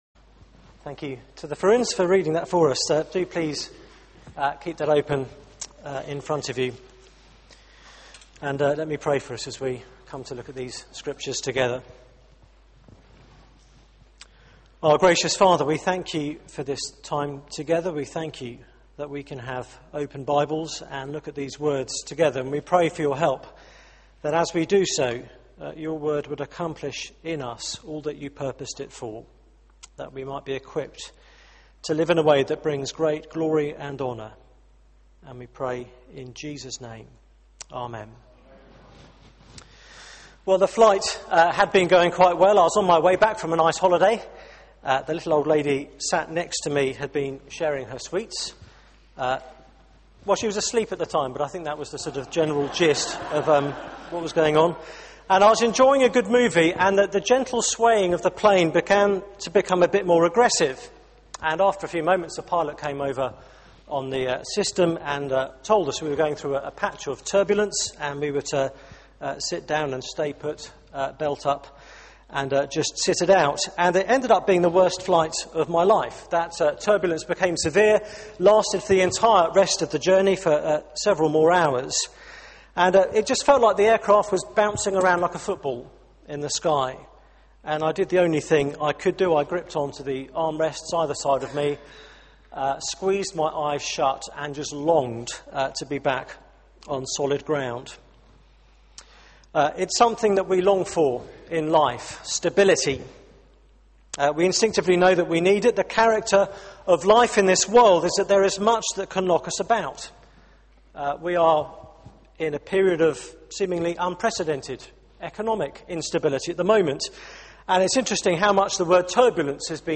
Media for 6:30pm Service on Sun 20th Nov 2011 18:30 Speaker
Series: Famous last words Theme: 'No one will take away your joy' Sermon